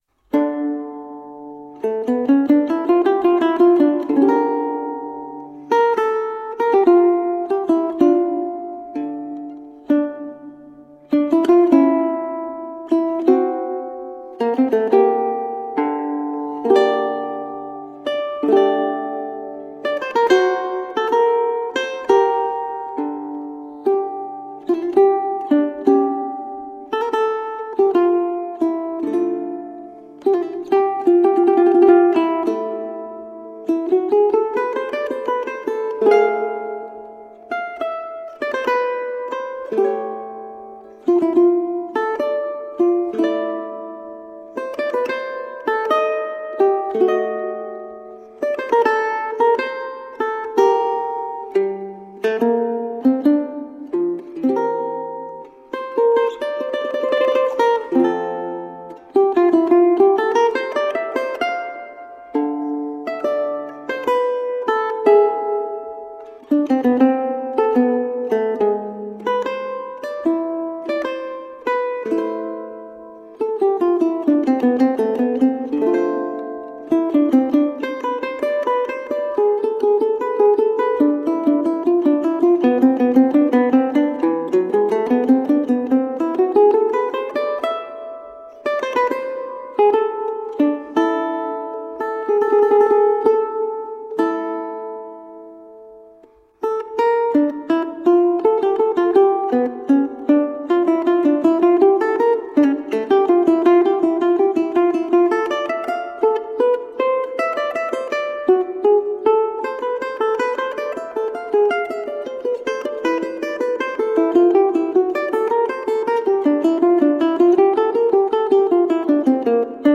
Colorful classical guitar.